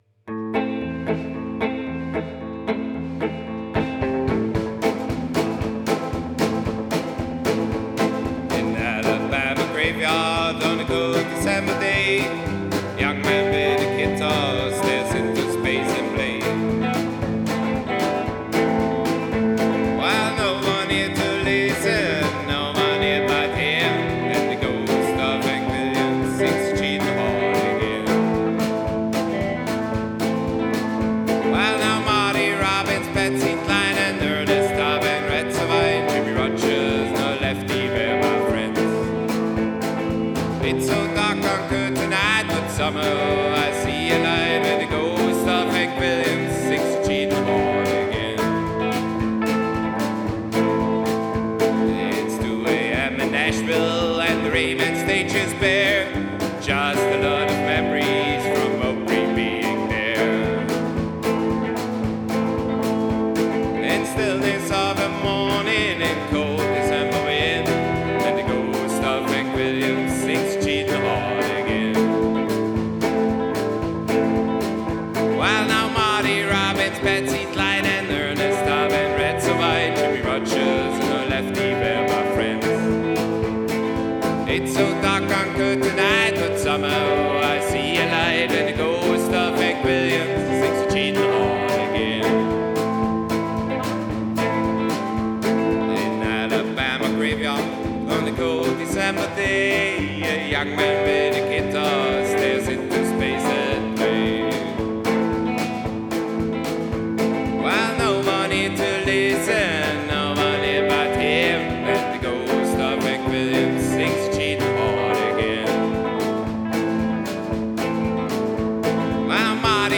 Genre: Psychobilly, Rockabilly, Country